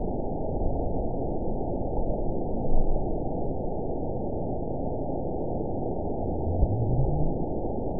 event 920243 date 03/08/24 time 20:37:26 GMT (1 year, 7 months ago) score 9.47 location TSS-AB01 detected by nrw target species NRW annotations +NRW Spectrogram: Frequency (kHz) vs. Time (s) audio not available .wav